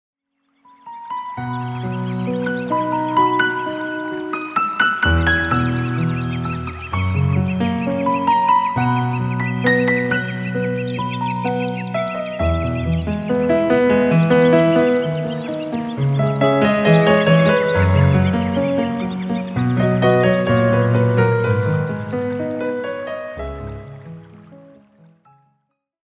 álbum instrumental